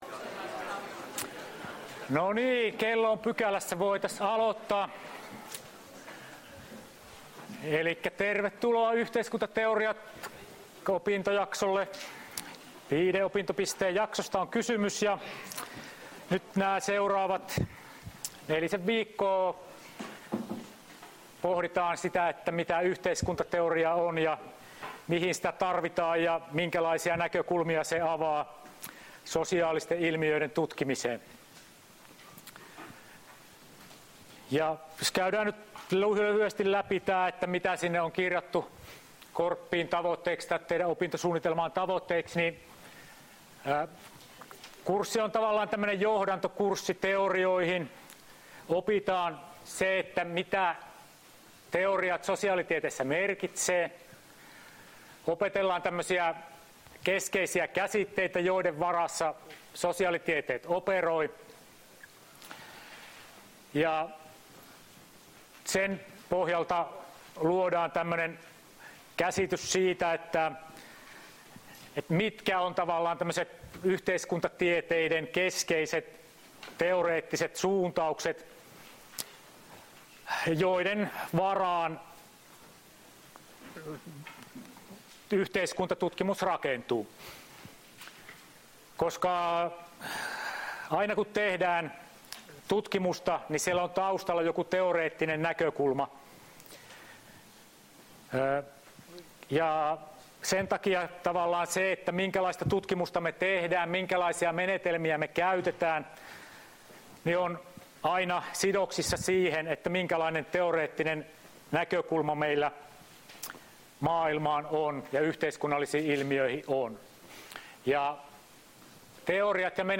Luento 29.10.2018